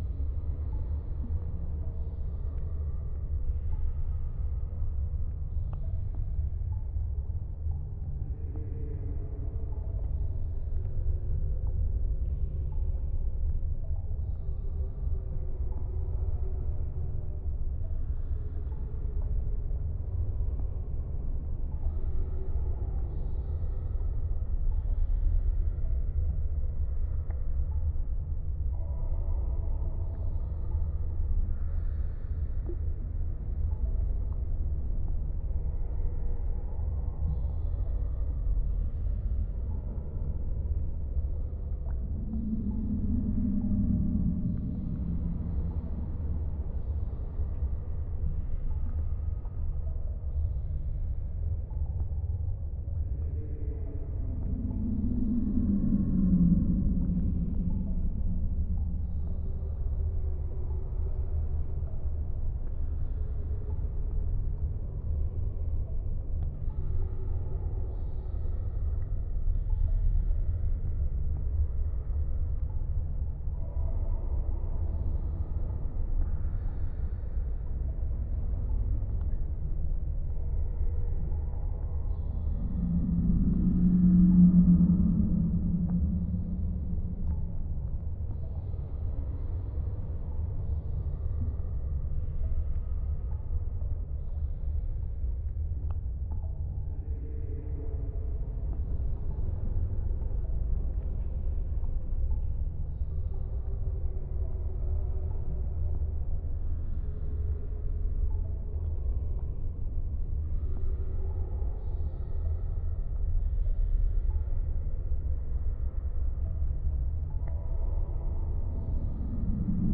underground_2.ogg